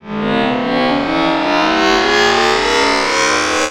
SCIFI_Up_05_mono.wav